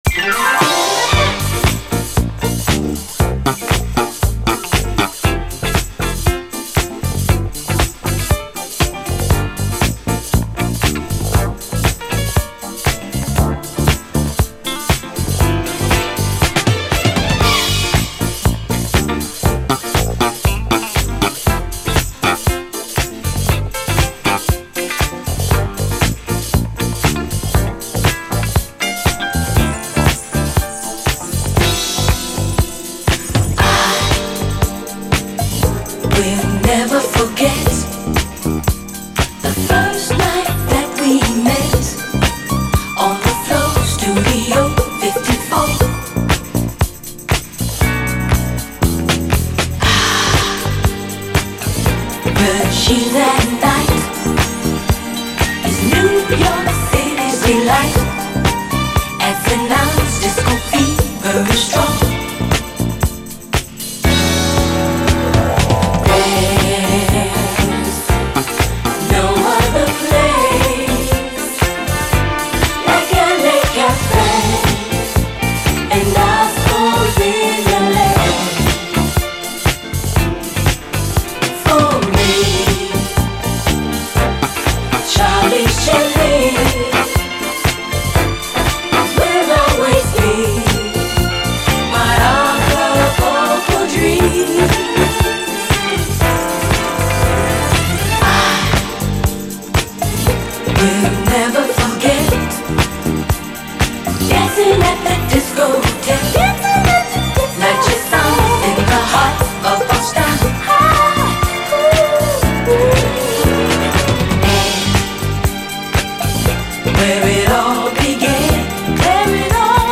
SOUL, 70's～ SOUL, DISCO
ジワジワとゾクゾクするようなゴージャス・ムードがタマンナイです。